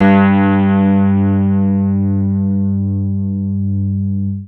Index of /90_sSampleCDs/Zero G Creative Essentials Series Vol 26 Vintage Keyboards WAV-DViSO/TRACK_21